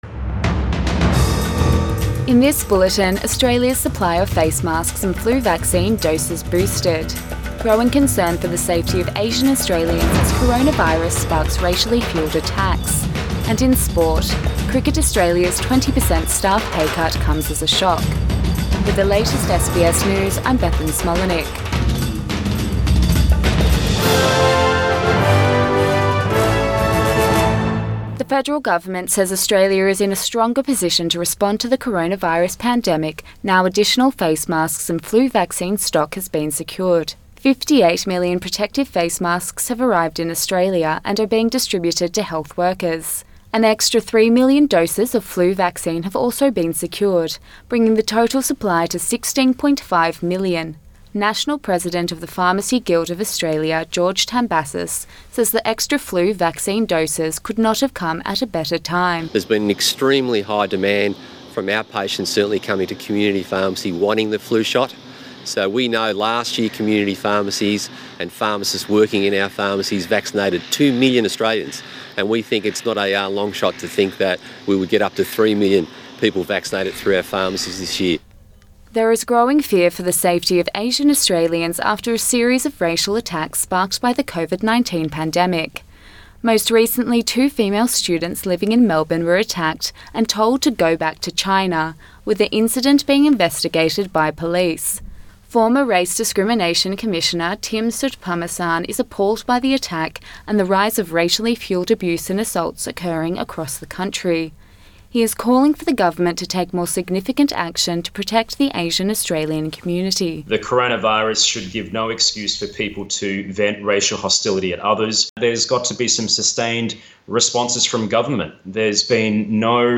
PM bulletin 19 April 2020